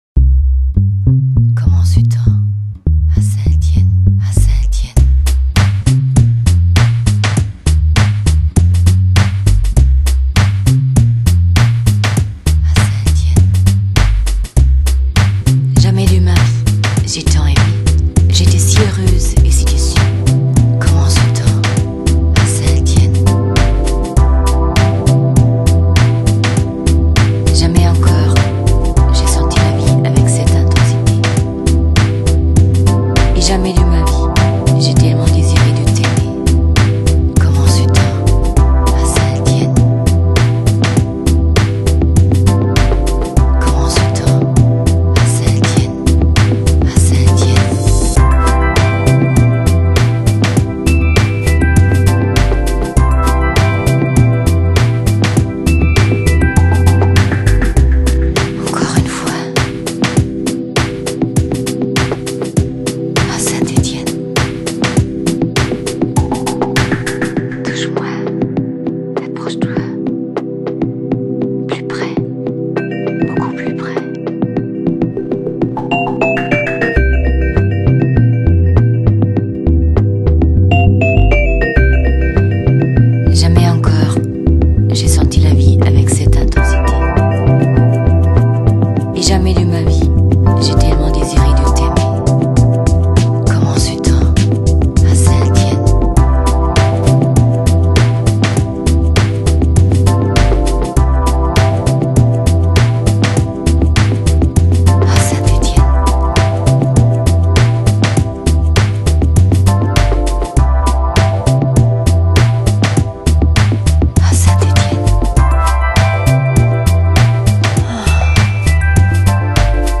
Genre: Electronic, Downtempo, Lounge
Cool, groovy, jazzy tracks.